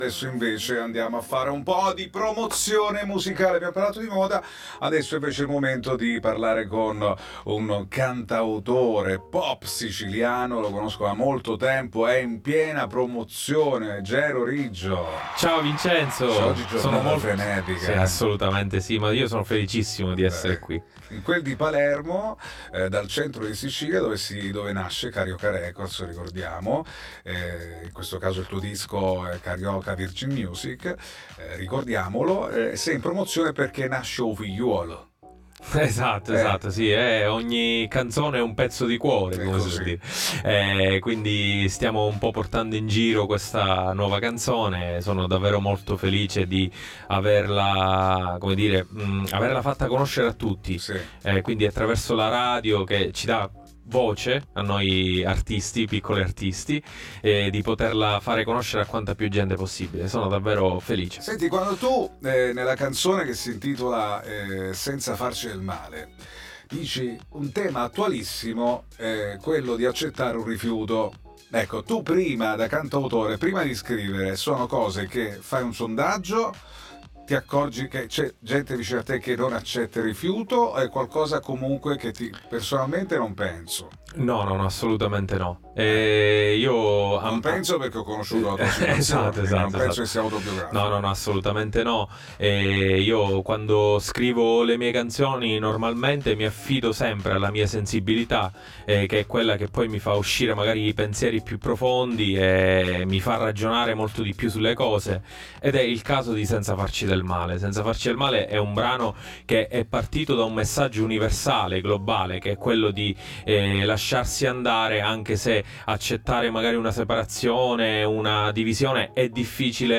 All Inclusive Interviste 10/12/2025 12:00:00 AM